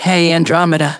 synthetic-wakewords
ovos-tts-plugin-deepponies_Adachi Tohru_en.wav